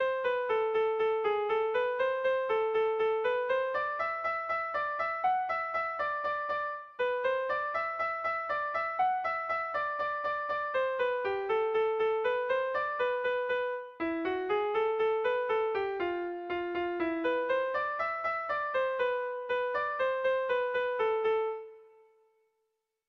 Erromantzea
Seiko berezia, 4 puntuz (hg) / Lau puntuko berezia (ip)
ABDE